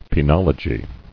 [pe·nol·o·gy]